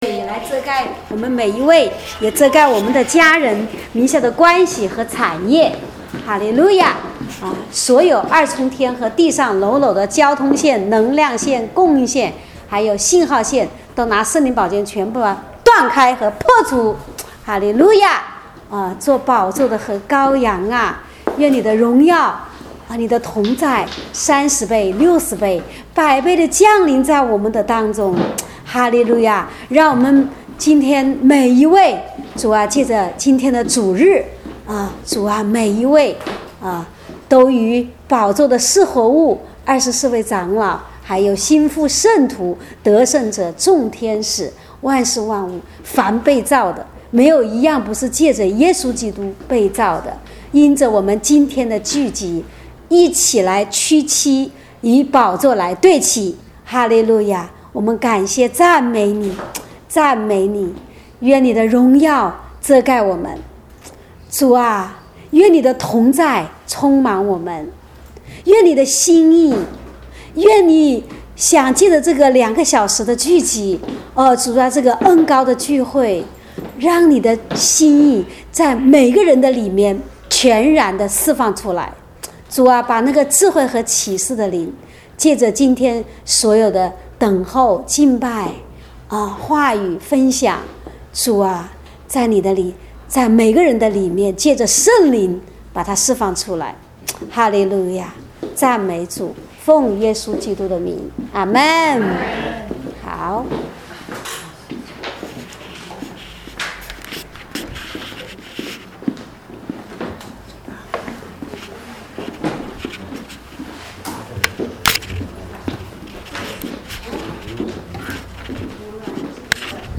正在播放：--主日恩膏聚会录音（2014-10-05）